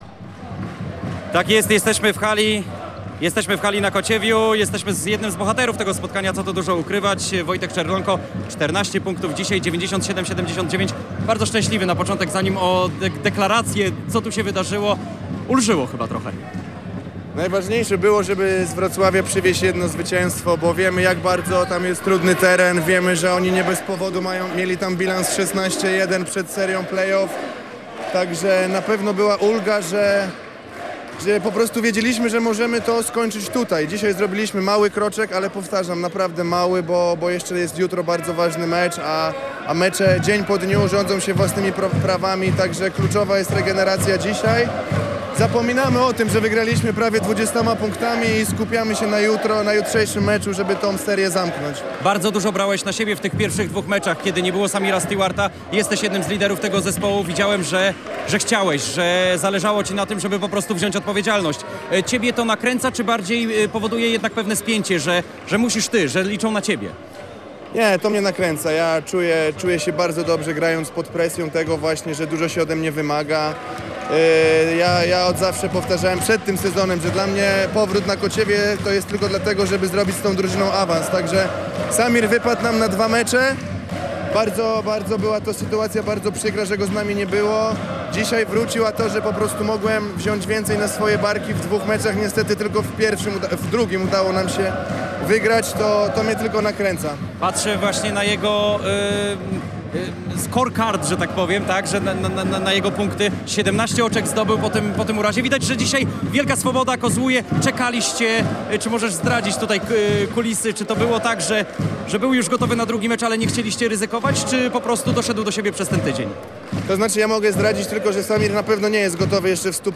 Koszykarz poświęcił nam kilka minut tuż po zakończeniu spotkania, dającego starogardzianom prowadzenie w serii do trzech wygranych 2:1:
W rozmowie „na gorąco”, na parkiecie tuż po końcowej syrenie zadeklarował, że jego celem jest awans do Ekstraklasy.